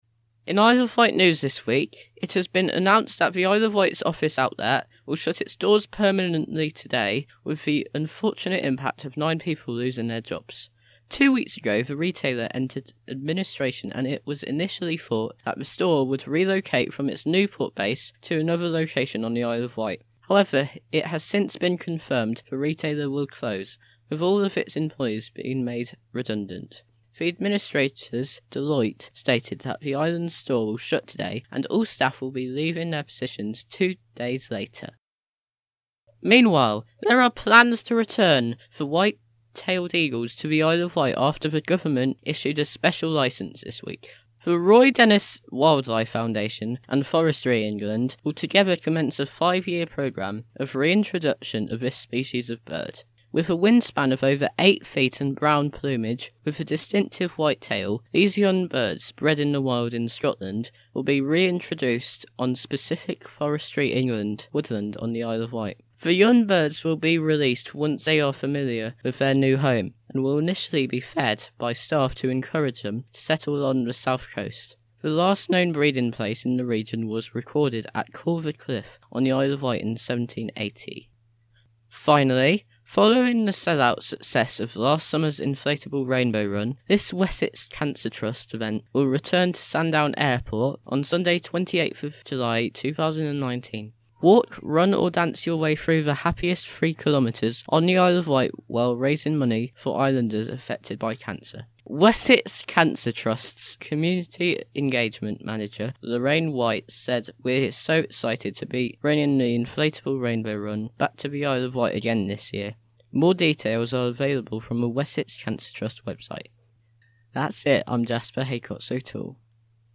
reads the Vectis Radio News